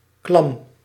Ääntäminen
Tuntematon aksentti: IPA: /klɑm/